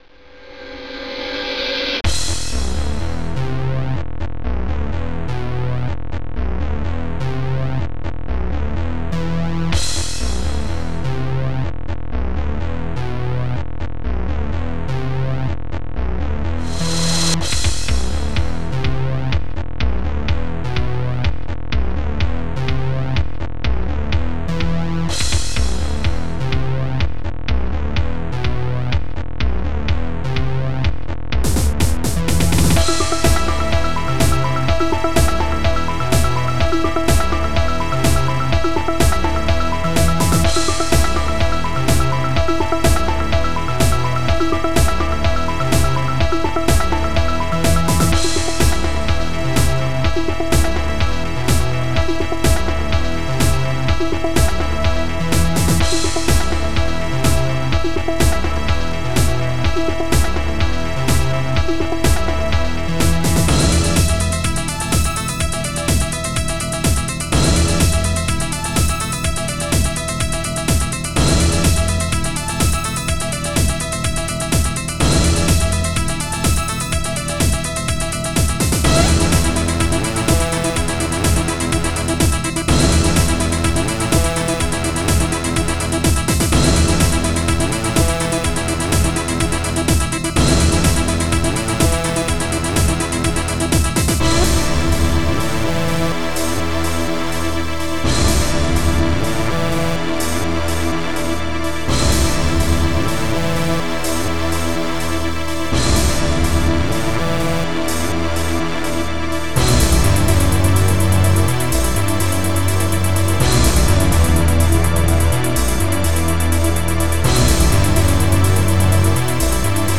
s3m (Scream Tracker 3)
bass drm
snare
hihat close
hihat open
crash symbal
bass
histring
synth hit
pipes